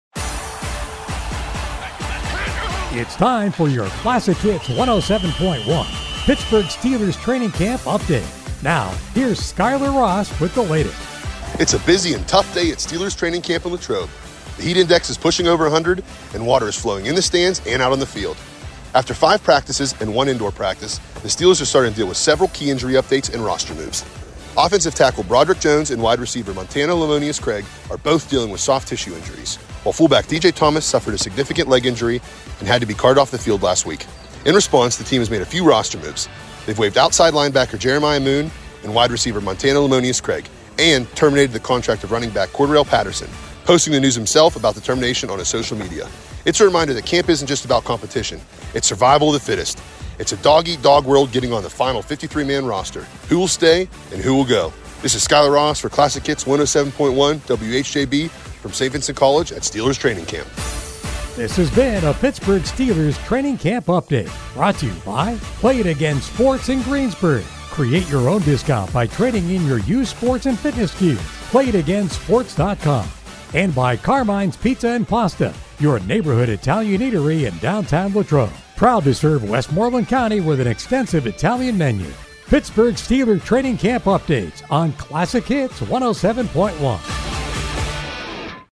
Our Greensburg affiliate WHJB is at Steelers Training Camp in Latrobe and filing three reports daily on the day’s activities and more.
July 30 – Report 3